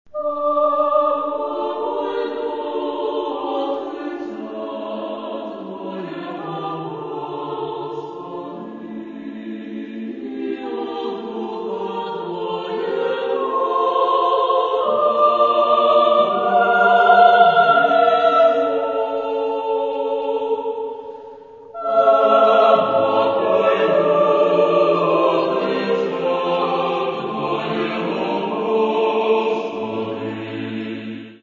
Духовні хорові твори